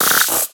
Cri de Bargantua dans Pokémon X et Y.